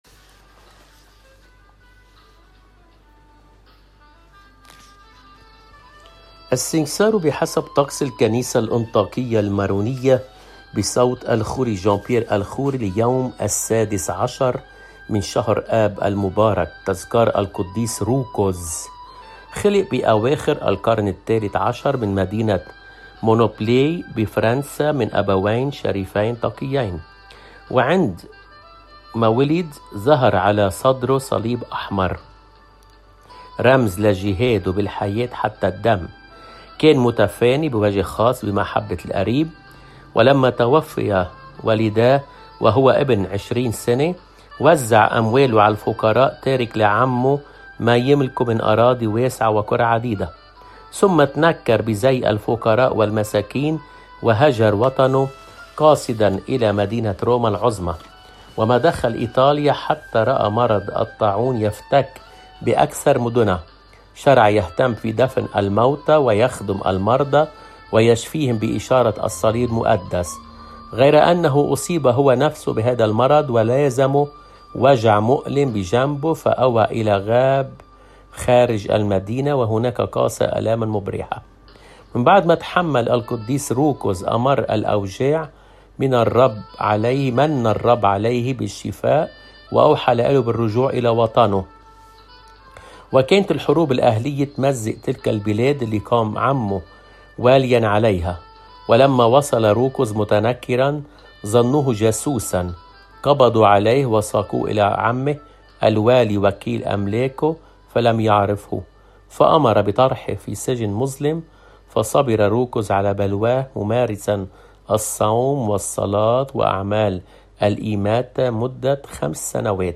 بصوت